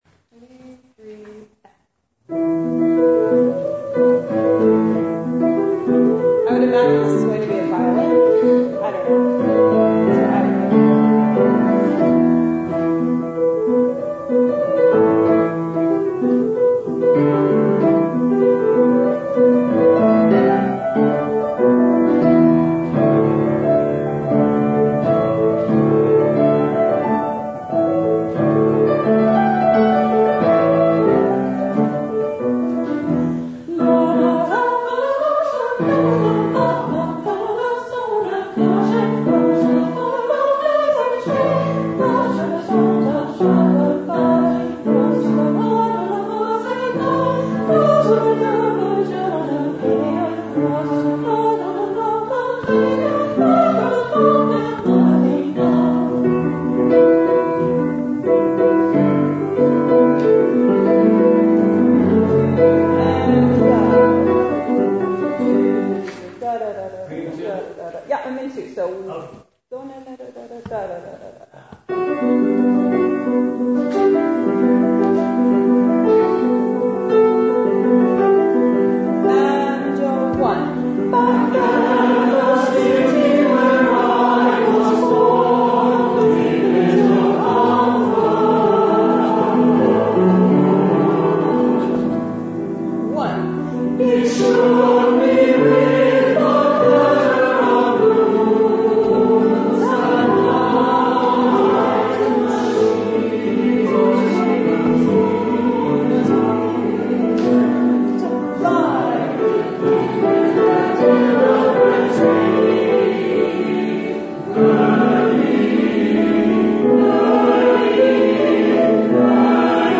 Maine State Rehearsal Files